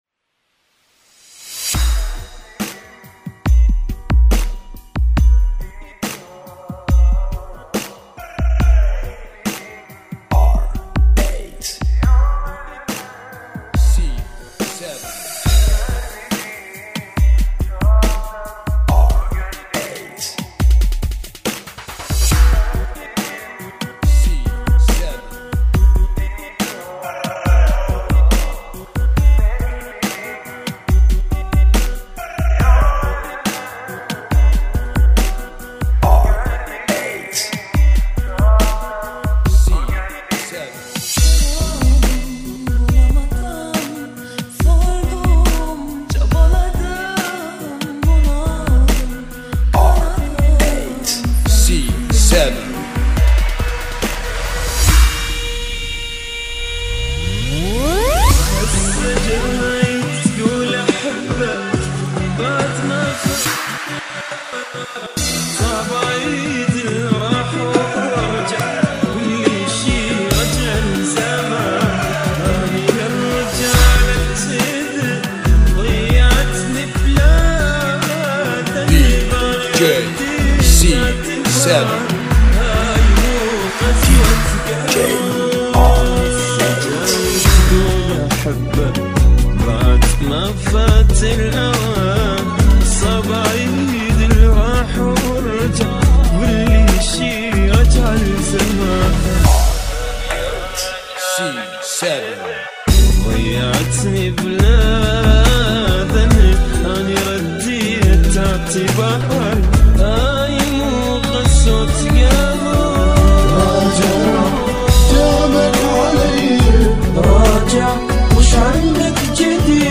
BPM 70